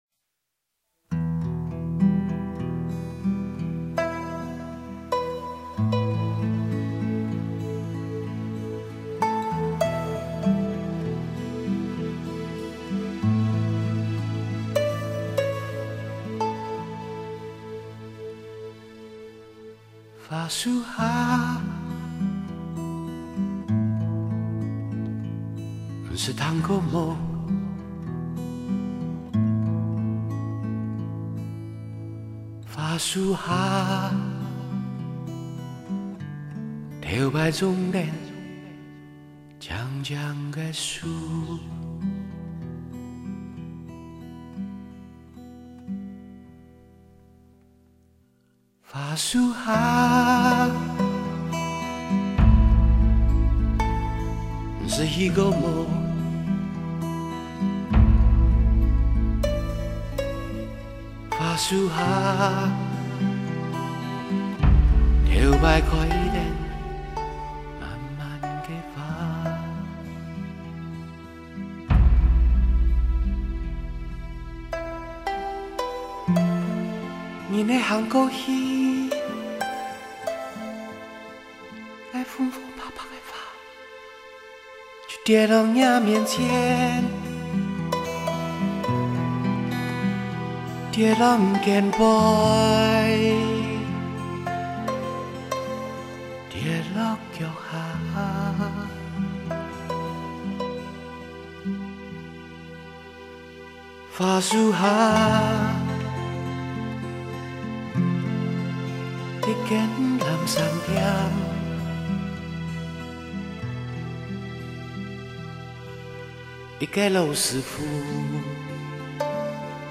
绝美歌声